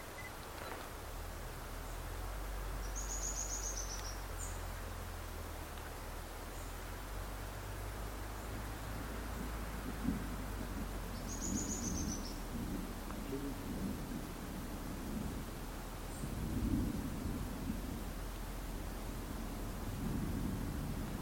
Streaked Xenops (Xenops rutilans)
Location or protected area: Parque Provincial Esmeralda
Condition: Wild
Certainty: Observed, Recorded vocal